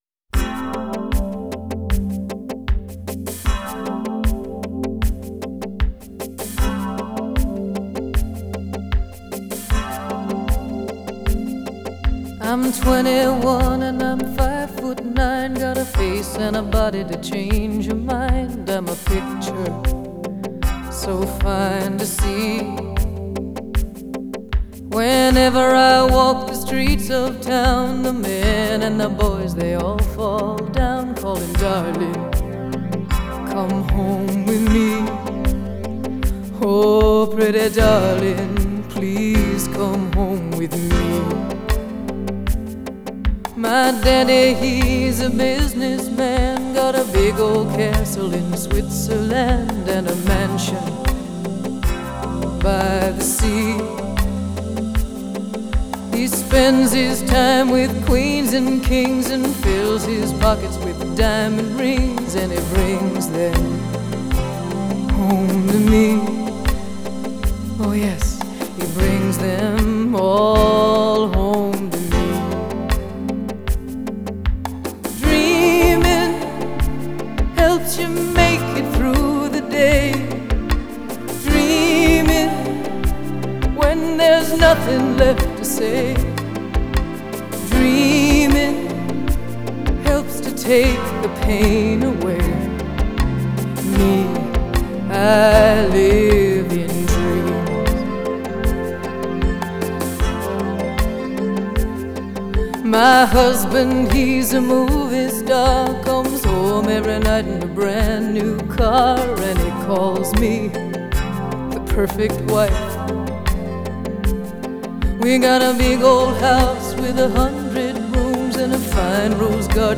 Genre: Pop